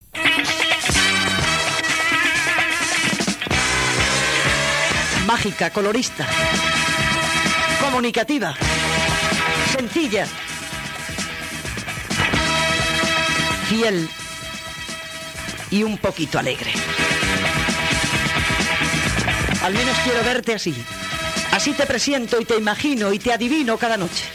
Fragment d'un inici de programa
Entreteniment